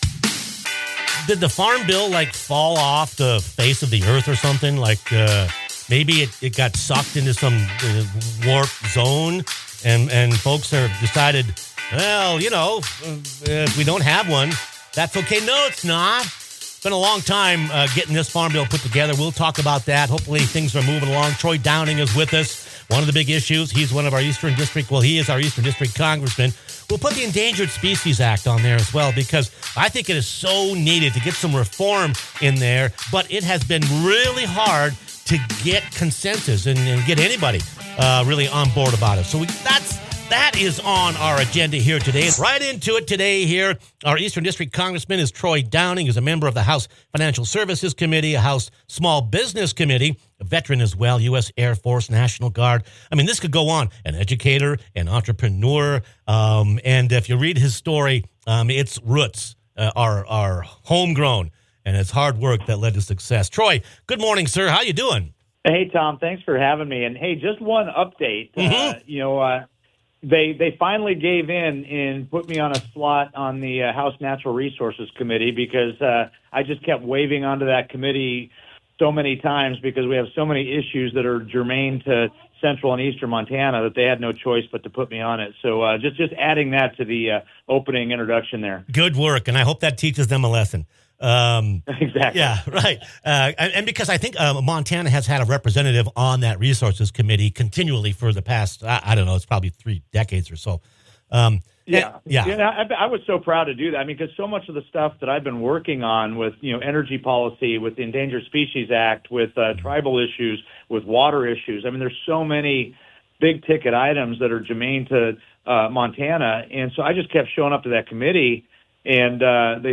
Eastern District Congressman Troy Downing joins the program to discuss some key federal issues affecting Montana, such as long-awaited reform of the Endangered Species Act and the long-overdue Farm Bill.